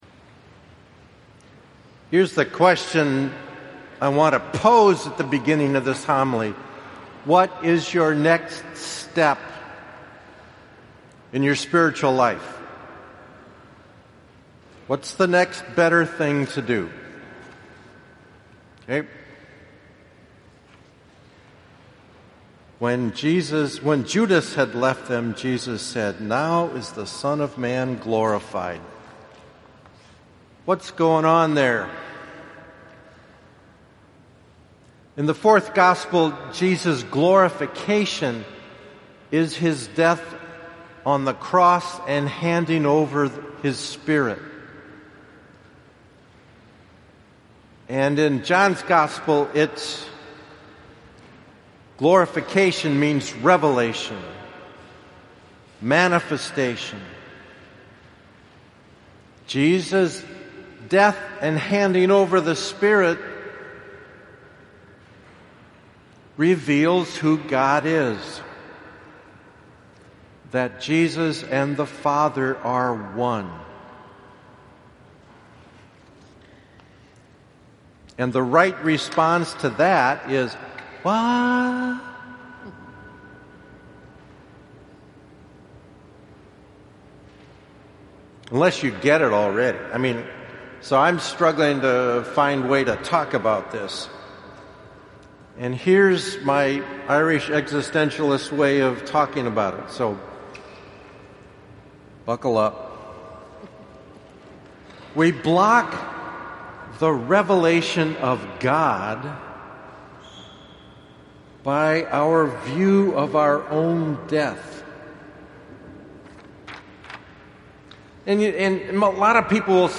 POSTS: Video Commentaries & Homilies (Audio)